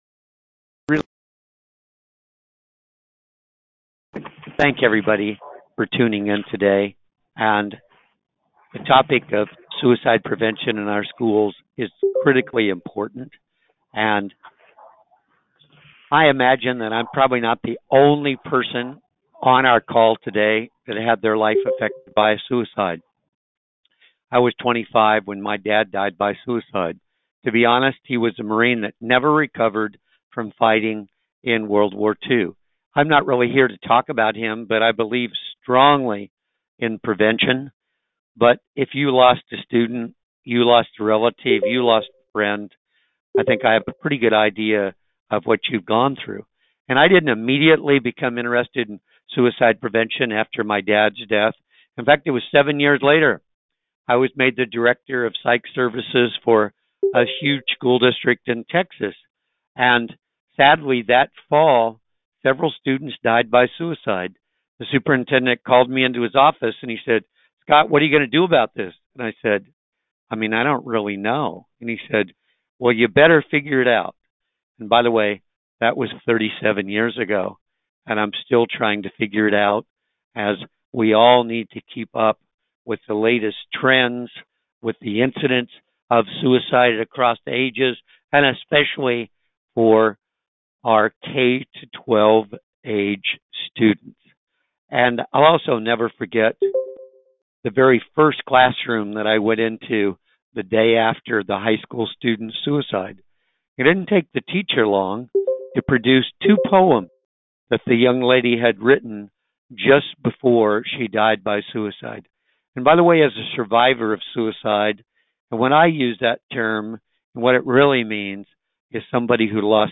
Webinar: Suicide Prevention in Schools